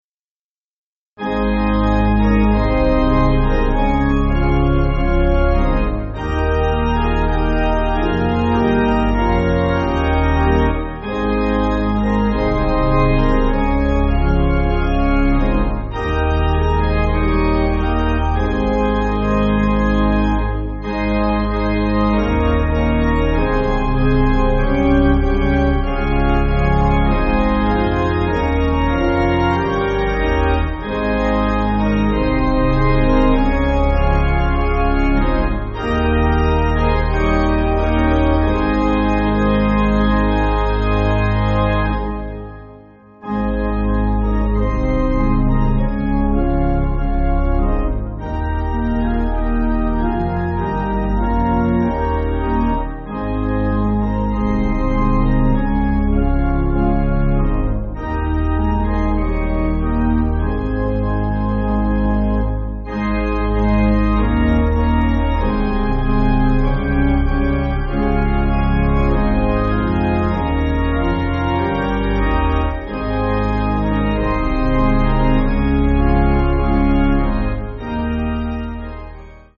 (CM)   5/Ab